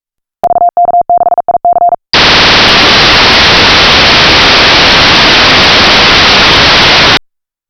VHF/UHF Digital Voice Software Modem
On Air Samples
Sample transmission (WAV File)